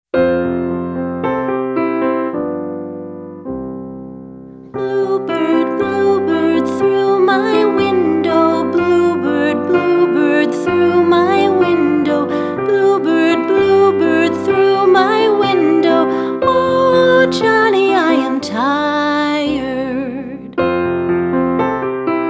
Traditional Singing Game